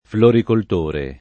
floricoltore [ florikolt 1 re ]